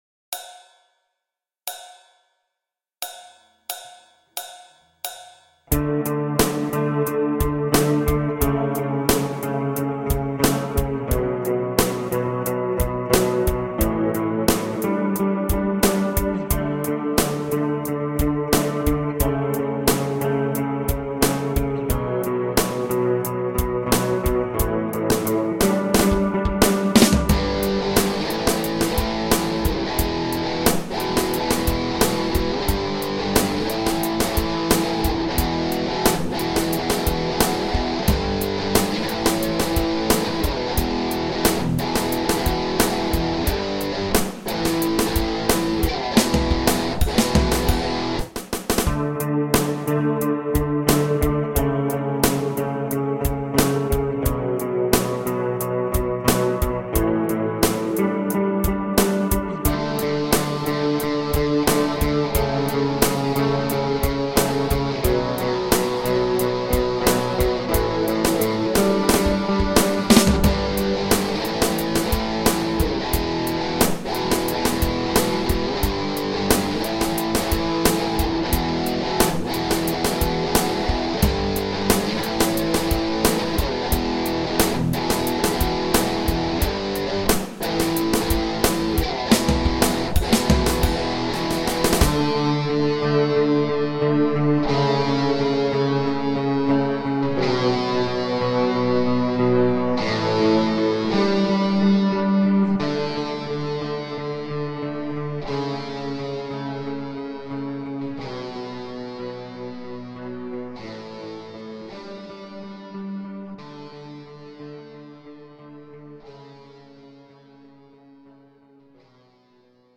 In key of Bm
Backing track
Autumn_Feelings_Jam_Track_in_Bm.ogg